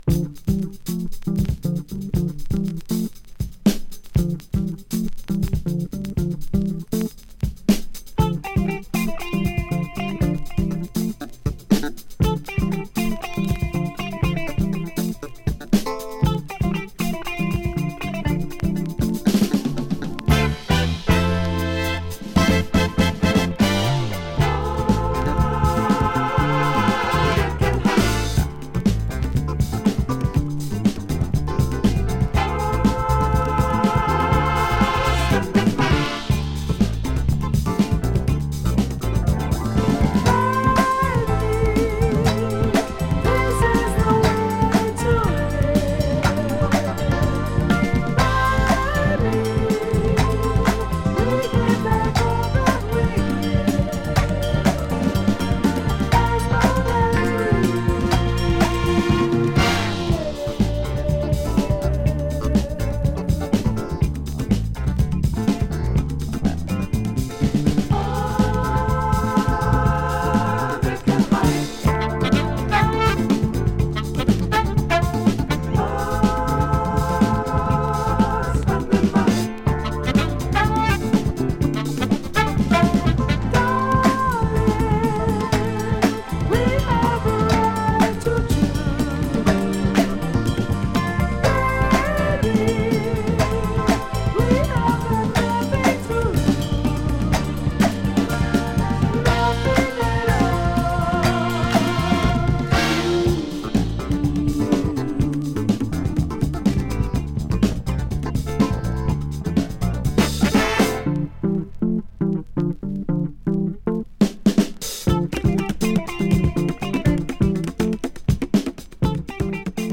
[FUNK]
Italian funky soul!
Nice coupling of funky soul & disco numbers